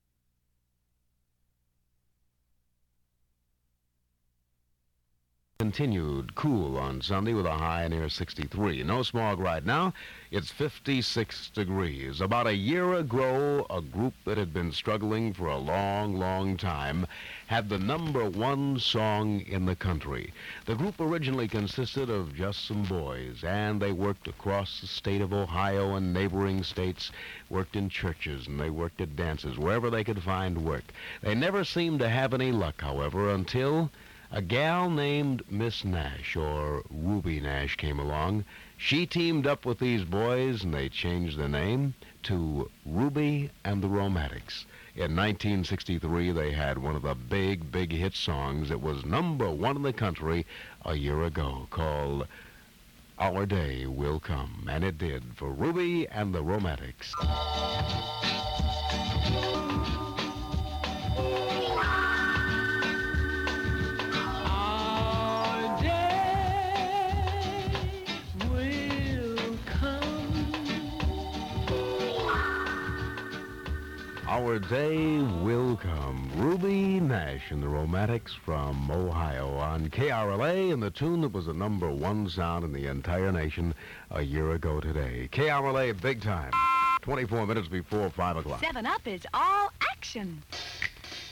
I have a tape of C Kasem on KRLA from 1964 that I have not got around at uploading bc I am sure the vultures will strike on that one but I suppose the main point is to get the stuff out there so people can hear it and I will upload at some point.
Left in 5 secs of dead air at the beginging in error.